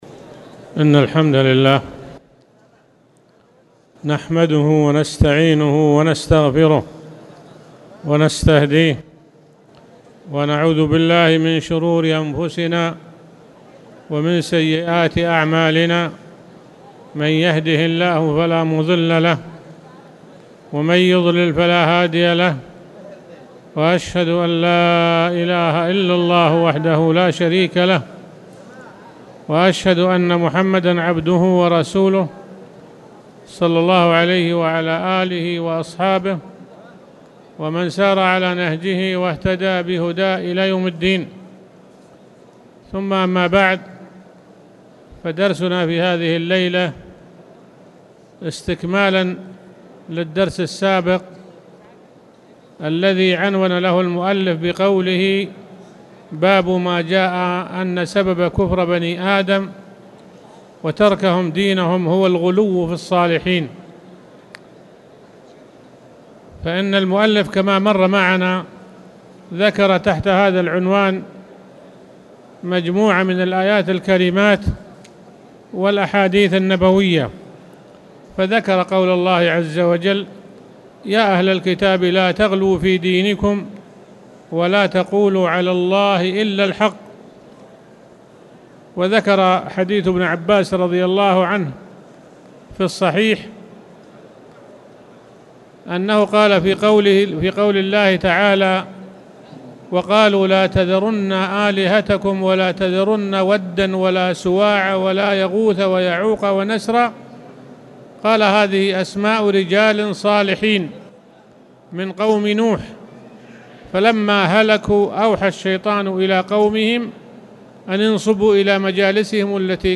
تاريخ النشر ١٠ جمادى الأولى ١٤٣٨ هـ المكان: المسجد الحرام الشيخ